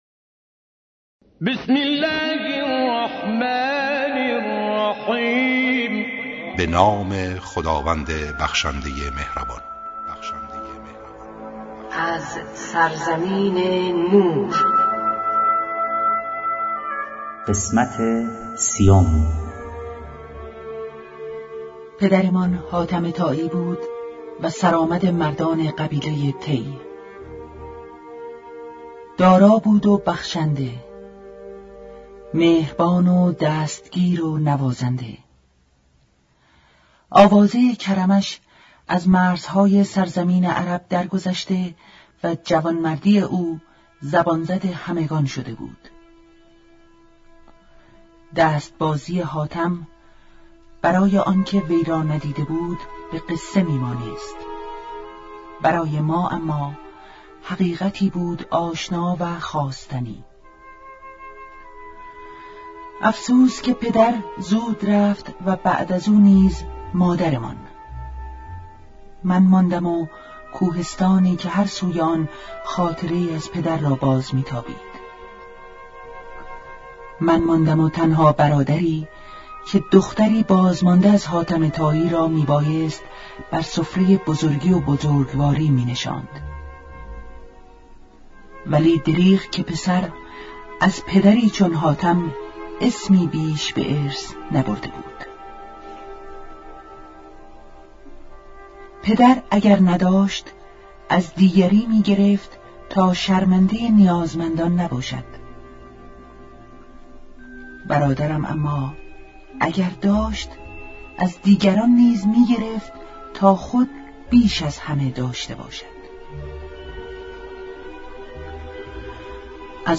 با اجرای مشهورترین صداپیشگان، با اصلاح و صداگذاری جدید
کتاب گویا